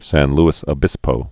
(săn lĭs ə-bĭspō)